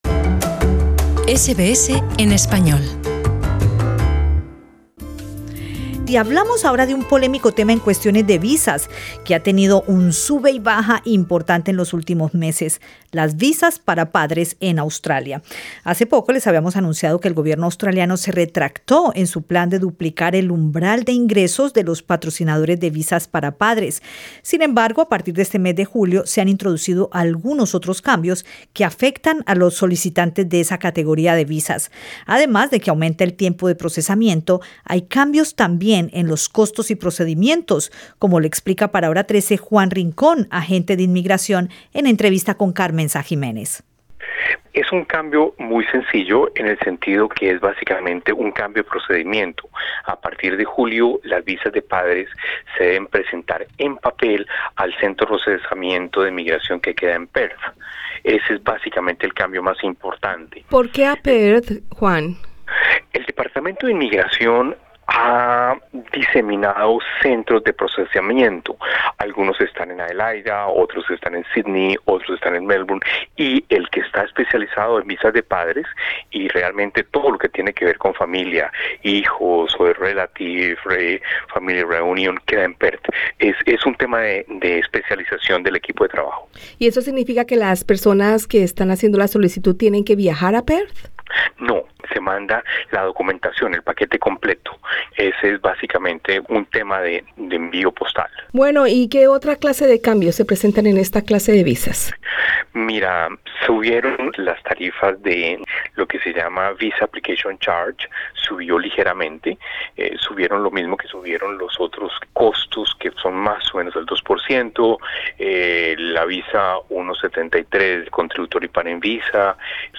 Escucha arriba en nuestro podcast la entrevista con el agente de inmigración